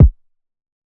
Kick 11.wav